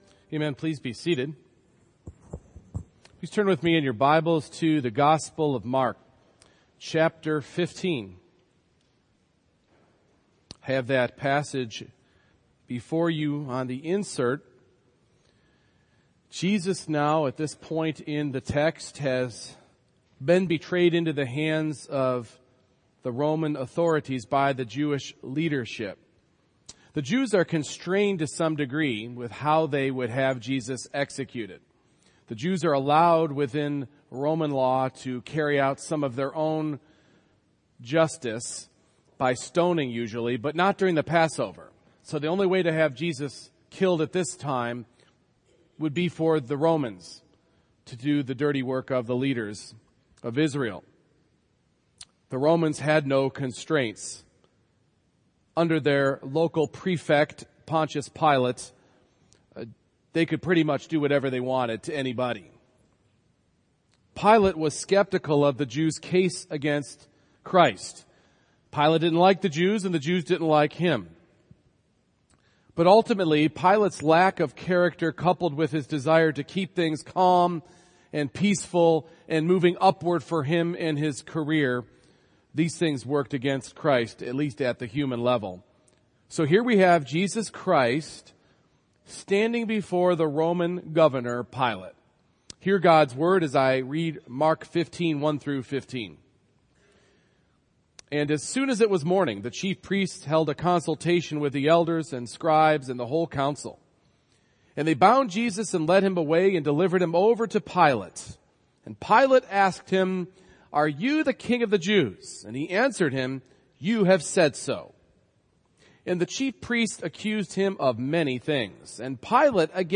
Mark 15:1-15 Service Type: Morning Worship As Christ stands before Pilate